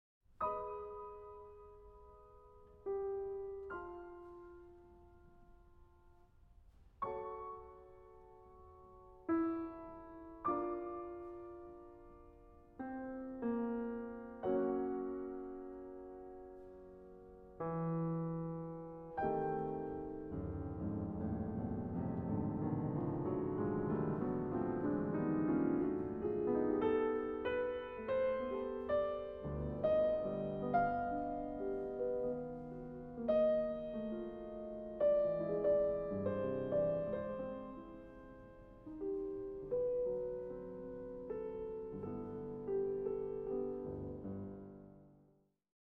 Works for piano